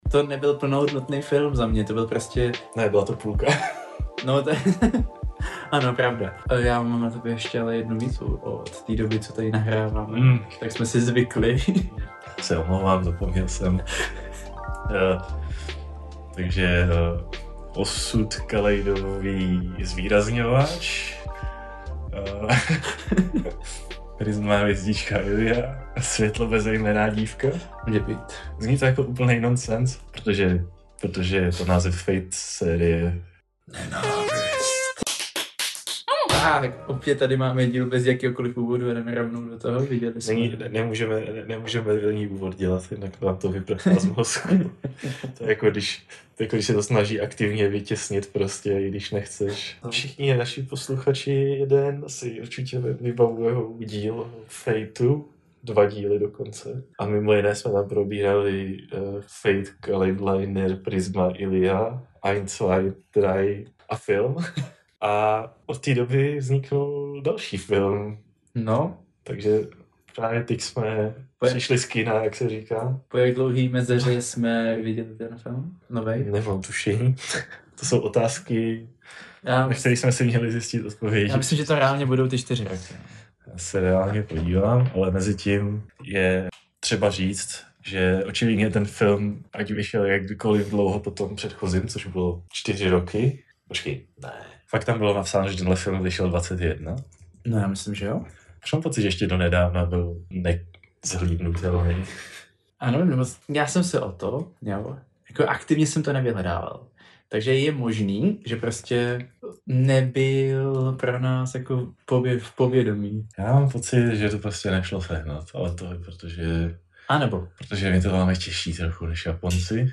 Smějeme se každé blbosti a navzájem po sobě házíme plyšáky.